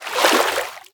minecraft / sounds / liquid / swim8.ogg
swim8.ogg